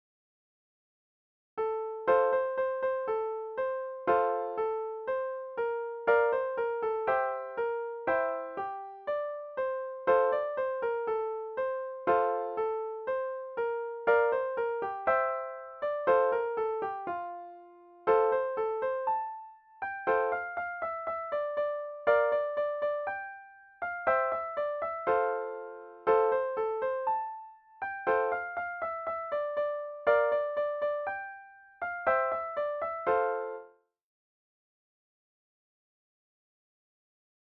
Deense volksmuziek